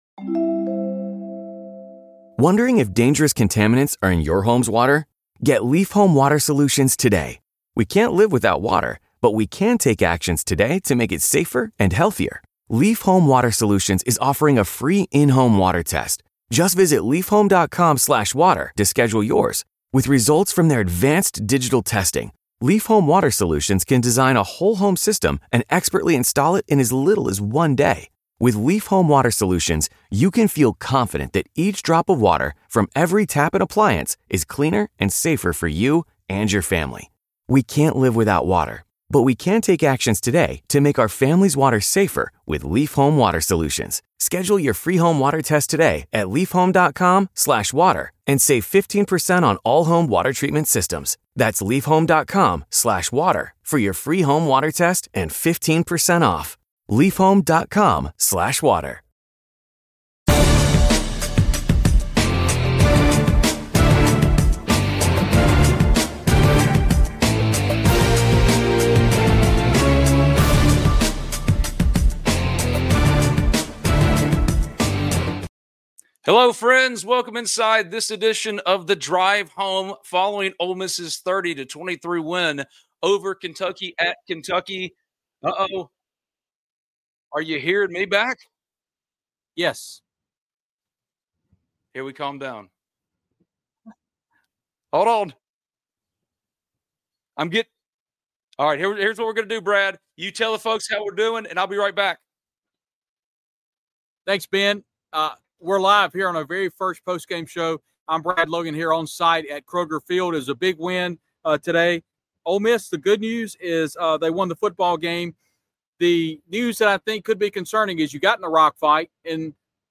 live from Lexington, break down Ole Miss (2-0, 1-0 SEC) football's 30-23 win at Kentucky (1-1, 0-1) in the SEC opener for both teams.